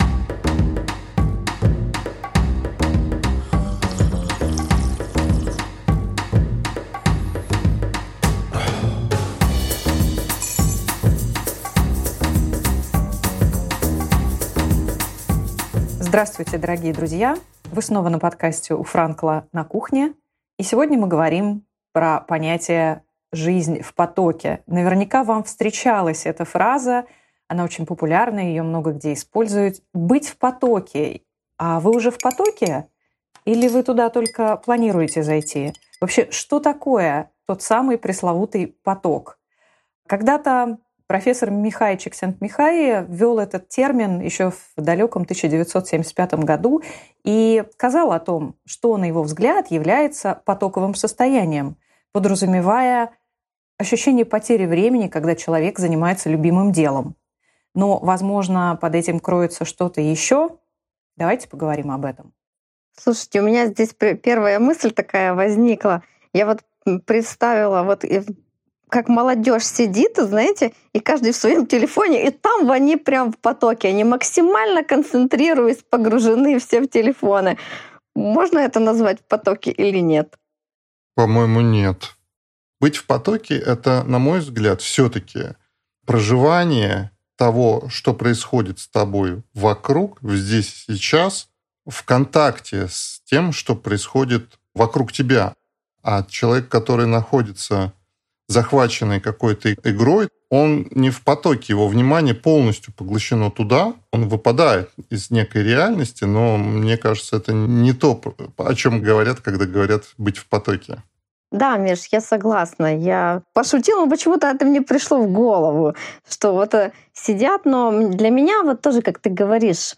Кто создал логотерапию, кому она подойдет лучше всего, в чем ее кардинальное отличие от других направлений психологической практики. Поделились некоторыми нашими любимыми лого-техниками, которые вы можете применить уже прямо сейчас. А вы слышали про логотерапию и Виктора Франкла раньше?Подкаст №030 от 30.04.2024. ______________________"У Франкла на кухне" – подкаст четырёх экзистенциальных психологов, которые шли по жизни разными путями, но однажды встретились.